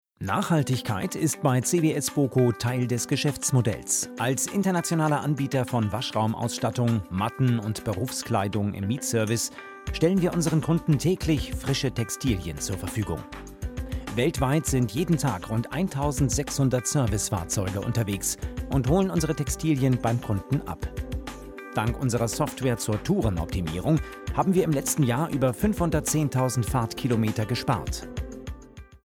Deutscher Sprecher für Radio & Fernsehen Industriefilme, Werbung, Reportagen, Dokumentationen, Overvoice, Nachrichten, Trailer mittlere Tonlage, Referenzen u.a. ARD, RB, NDR, BMW, Arte, Merz Pharmaceuticals, Messe Bremen, Kunsthalle Bremen
Kein Dialekt
Sprechprobe: eLearning (Muttersprache):